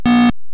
Bip 2
bip_02.mp3